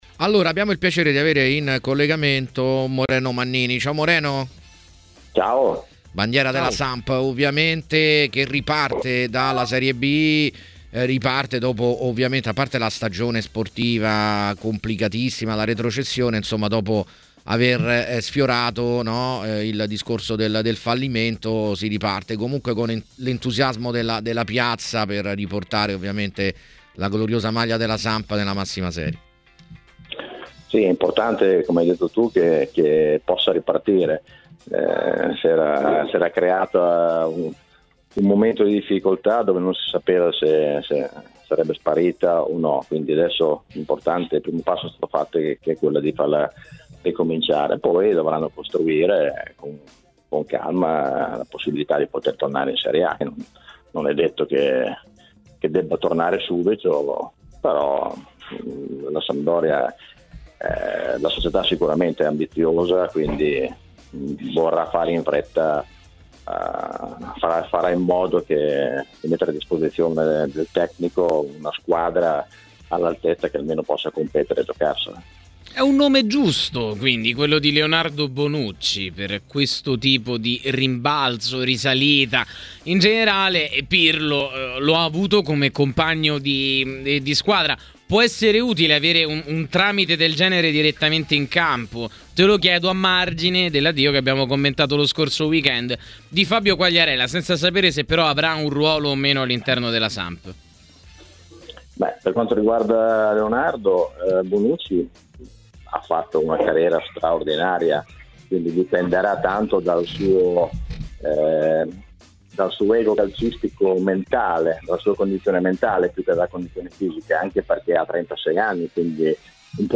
L'ex blucerchiato  Moreno Mannini, intervenuto a TMW Radio Piazza Affari, ha parlato anche delle voci che vorrebbero Leonardo Bonucci nel mirino della Sampdoria: